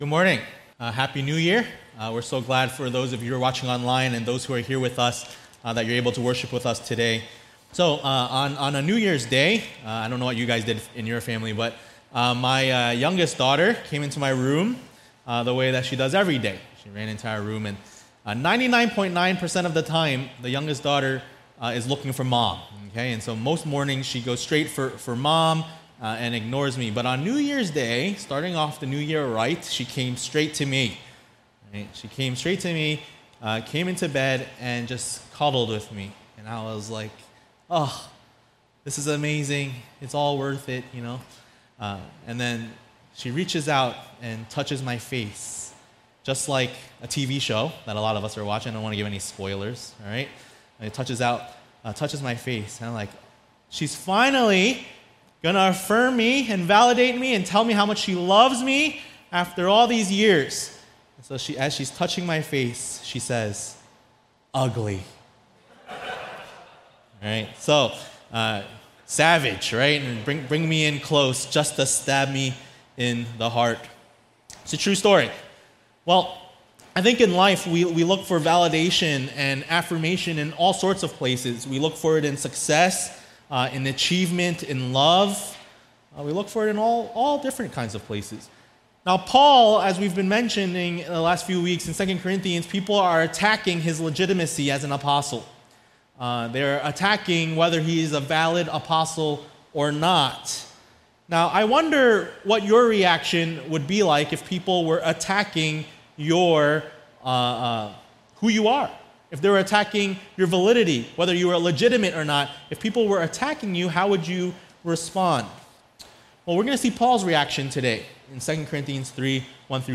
A message from the series "2 Corinthians ."